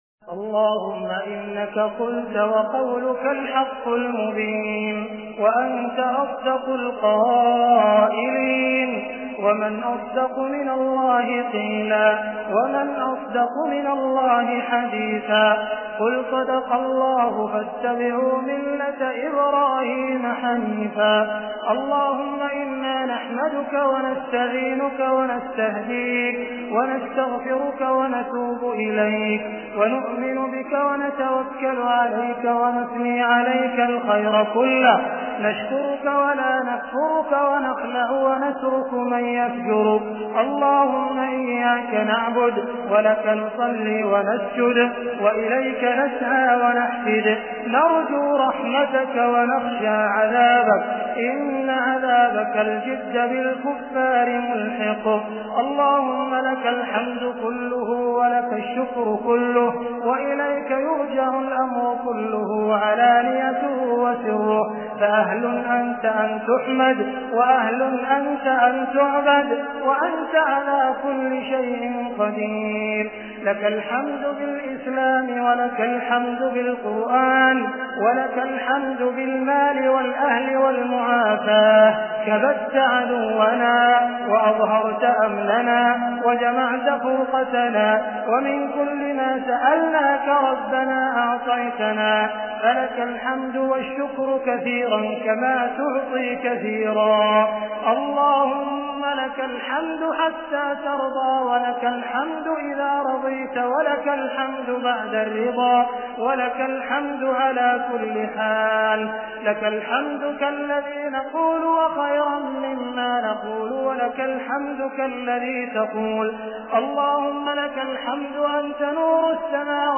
116 Qunut by AbdurRahman Sudais.mp3